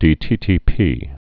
(dētētēpē)